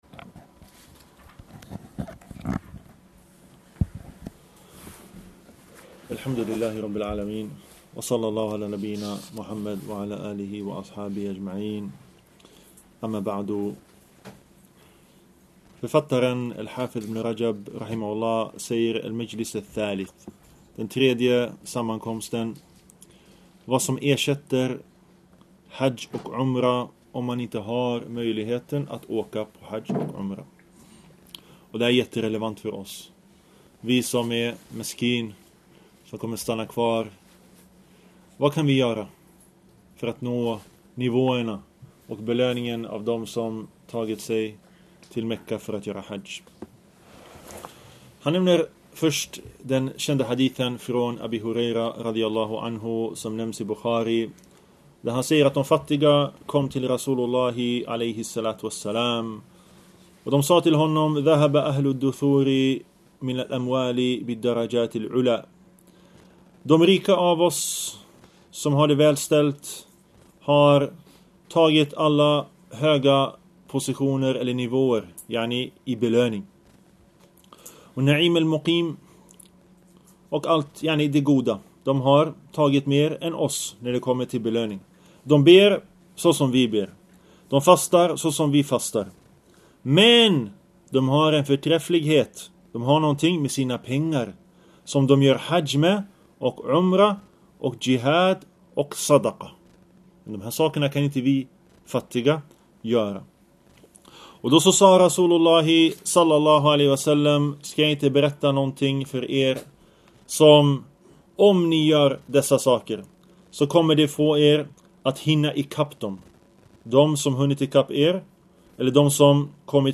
En föreläsning av